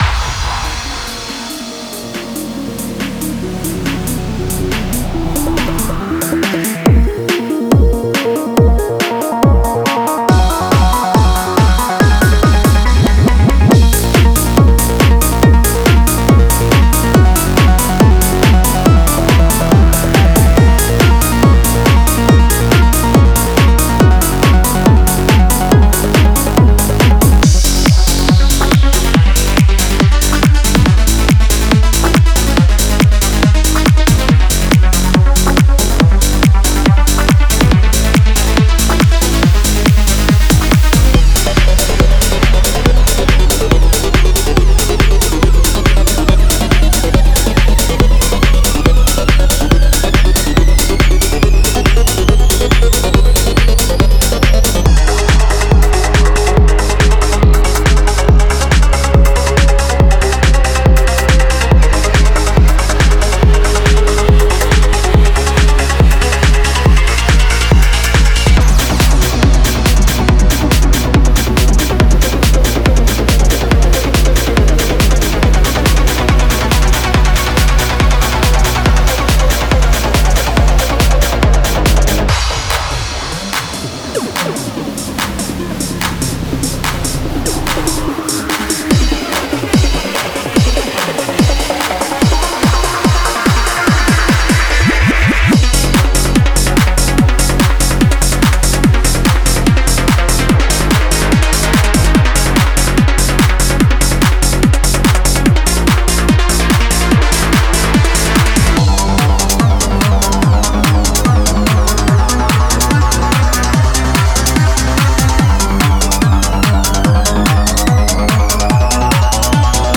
Genre:Psy Trance
デモサウンドはコチラ↓